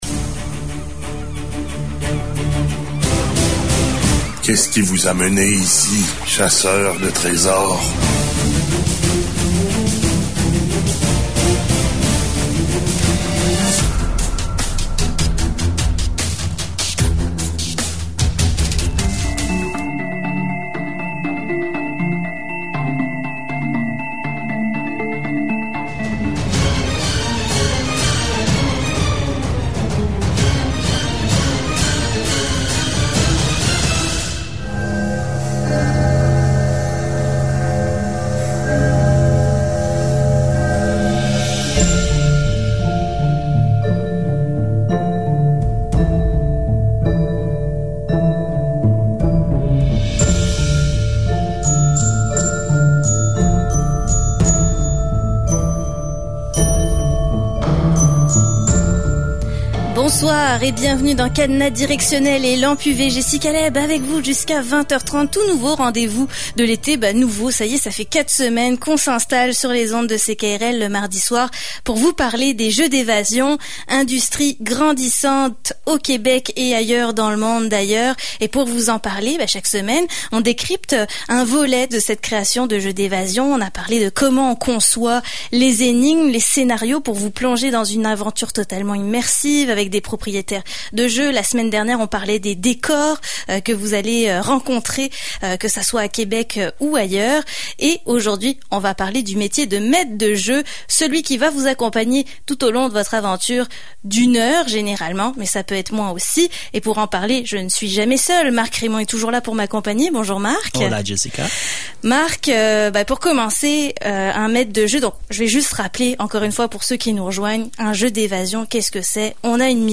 🎙 Avec nous, en studio :
📞 et en direct de son chalet :